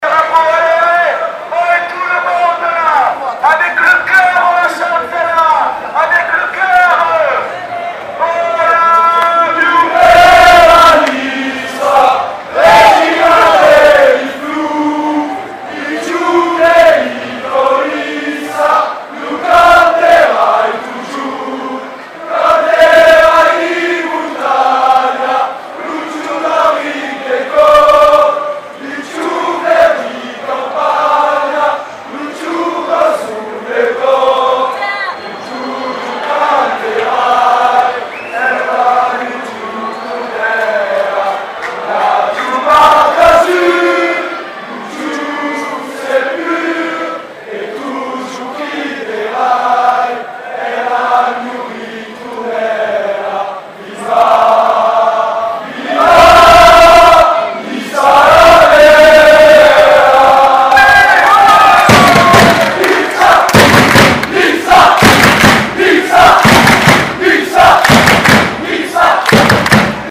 Chant de supporters